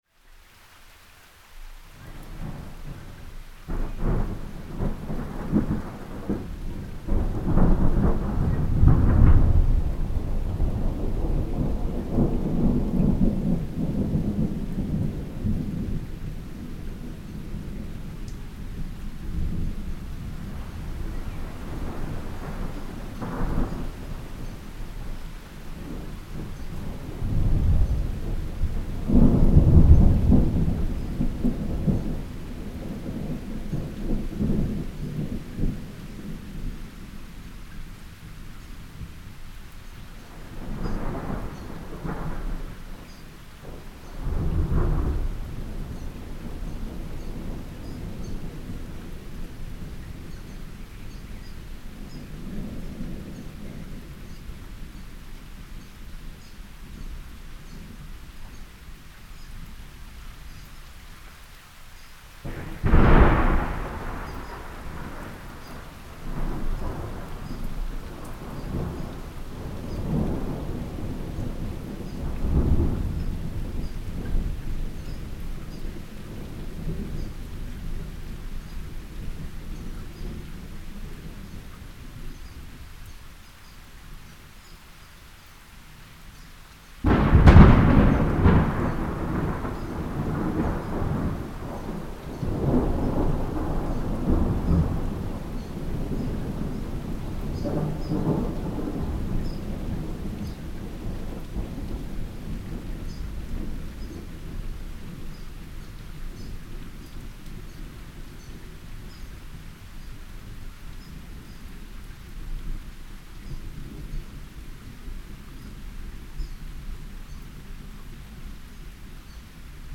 Gemafreie Sounds: Regen
mf_SE-6367-thundergrowl_1.mp3